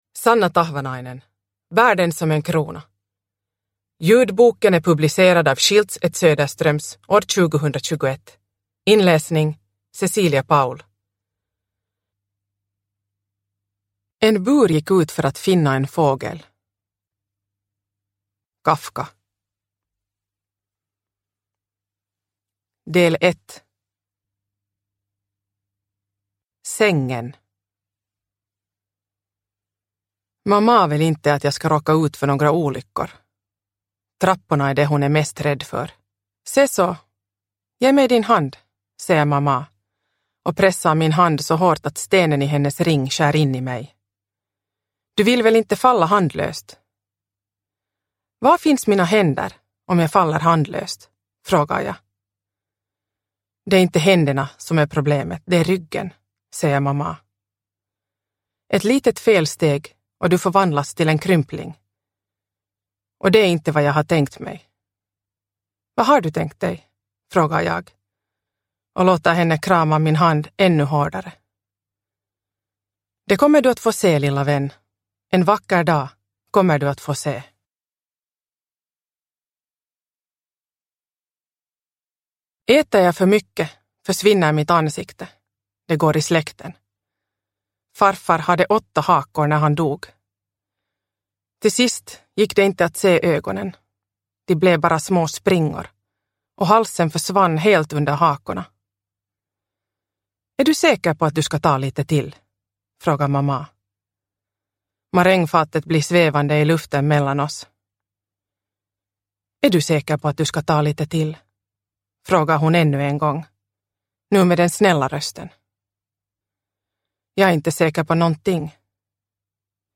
Bär den som en krona – Ljudbok – Laddas ner